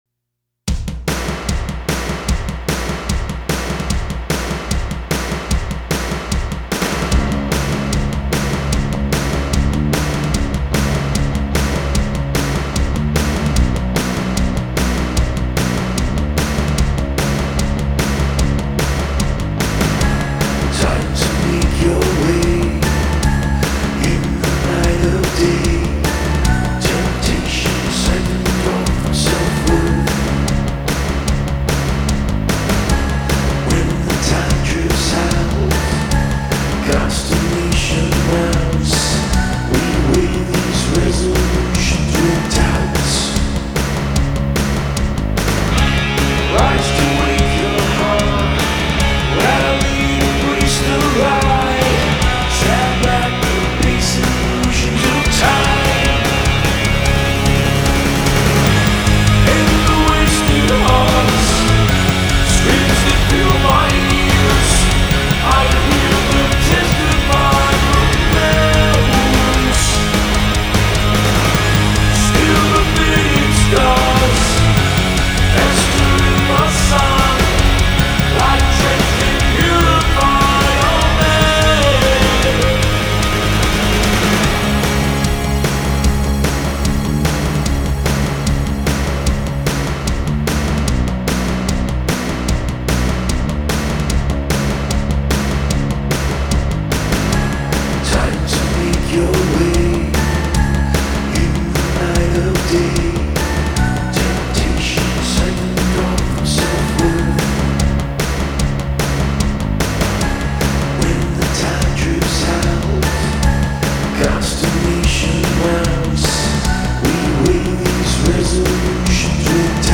New York post-Punk/Darkwave duo via their new album
guitar and vocals
bass guitar